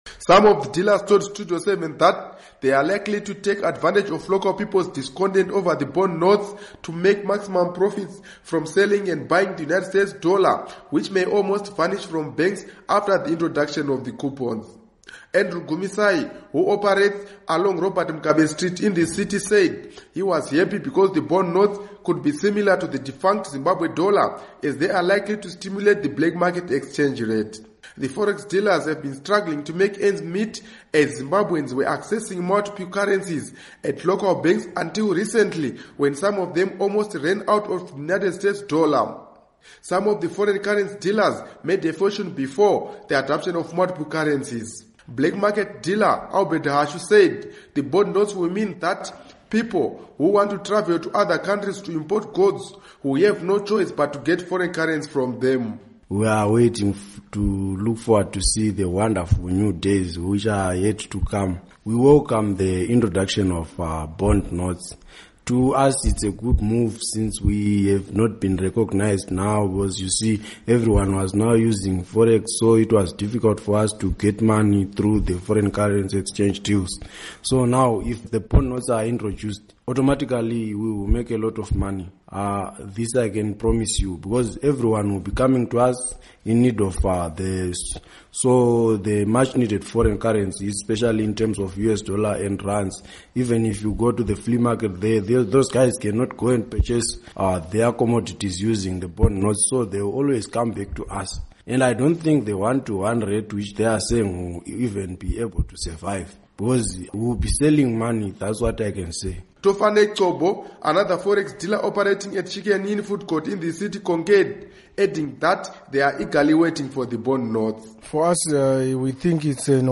Report on Bond Notes